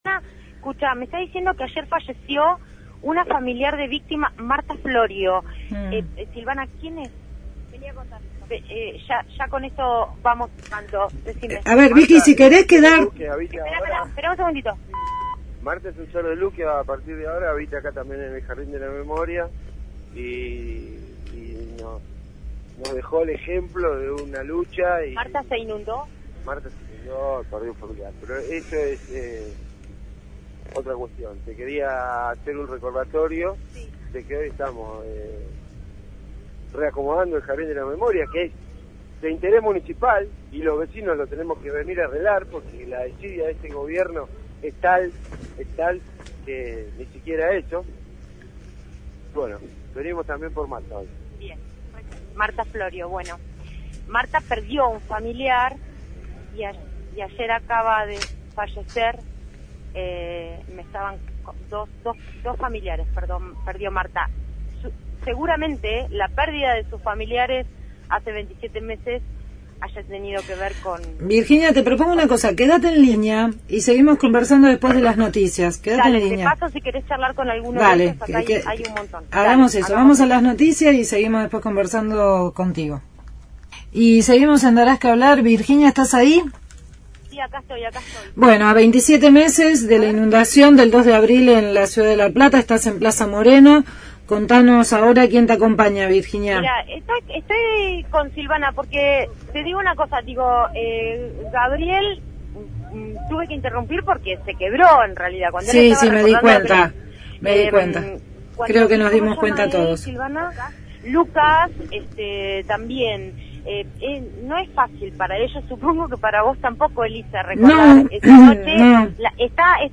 MÓVIL/ Reclamo a 27 meses de la inundación en La Plata – Radio Universidad